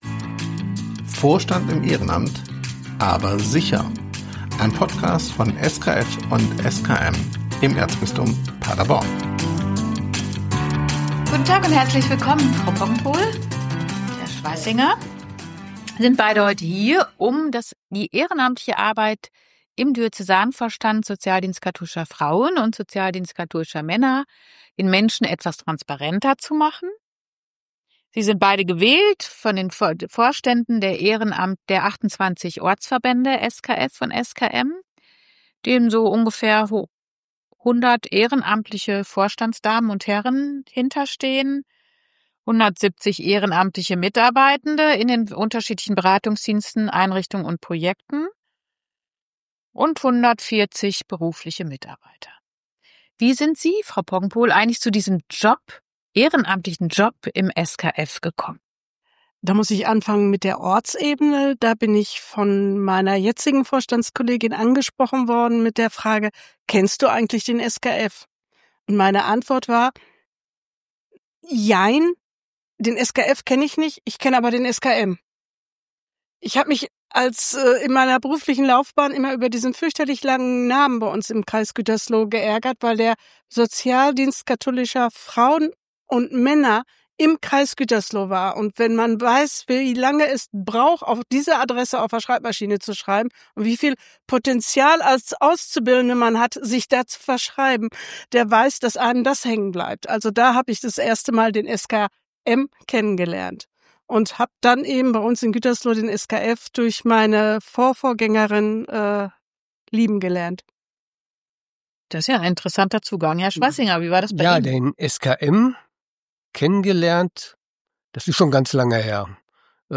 Wir beleuchten ihre persönlichen Wege zum Engagement und die Herausforderungen, denen sie in ihren Verantwortungsbereichen gegenüberstehen. Sie teilen ihre Erfahrungen und Highlights und betonen die Bedeutung eines unterstützenden Umfelds für die Ehrenamtlichen. Das Gespräch geht auch der Frage nach, wie der Glaube ihre Motivation beeinflusst und reflektiert den zeitlichen Aufwand, der mit ihrem Engagement verbunden ist.